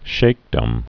(shākdəm, shēk-)